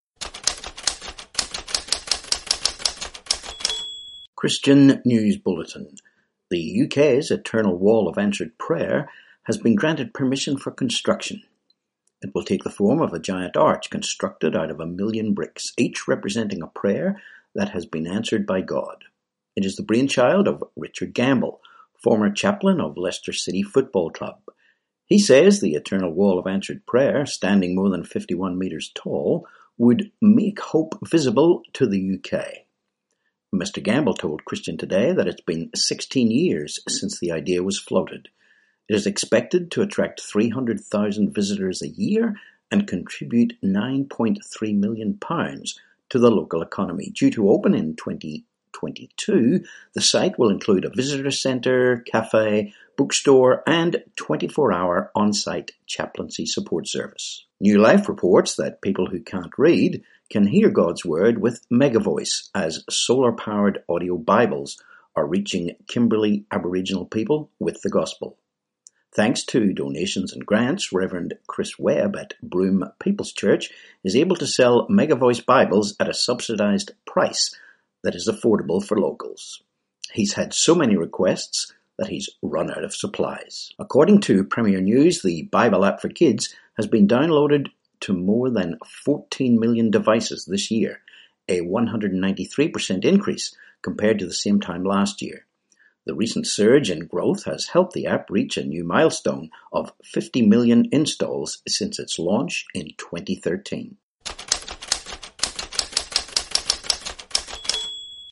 11Oct20 Christian News Bulletin